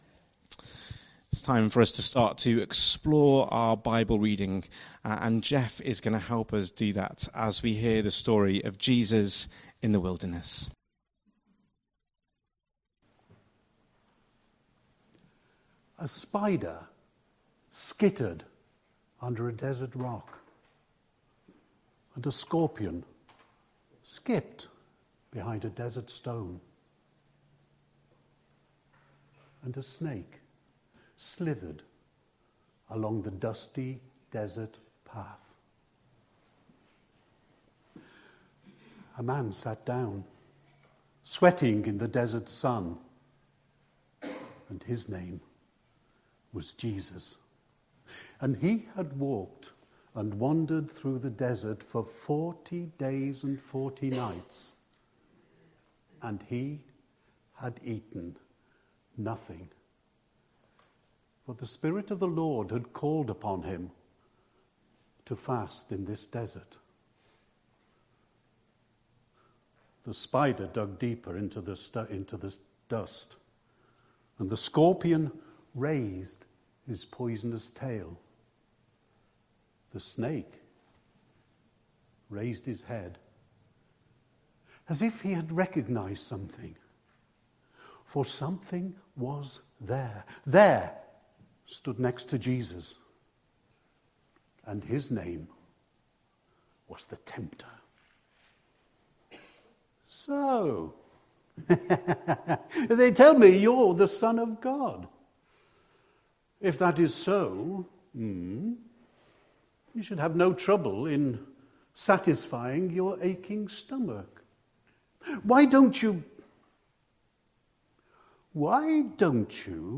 1st March 2026 Sunday Reading and Talk - St Luke's
All-In Service exploring the story of Jesus in the Wilderness.